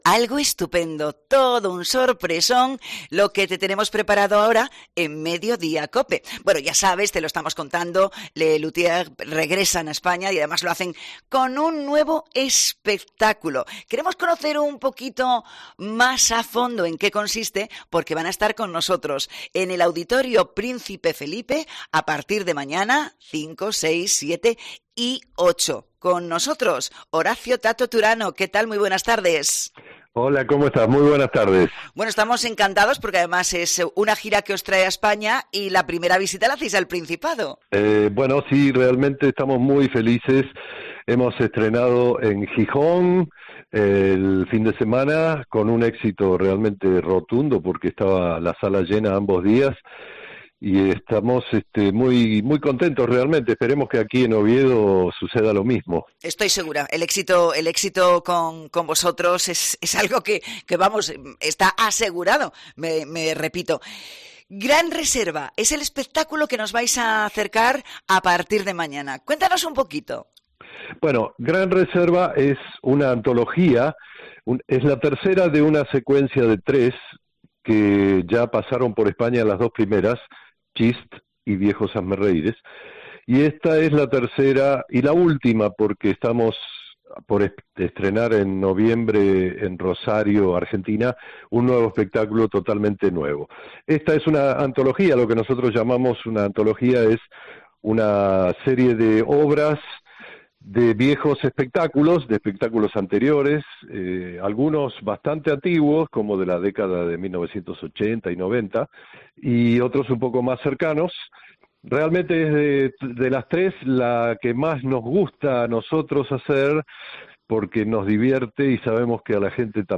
Entrevista con Horacio Tato Turano, integrante de Les Luthiers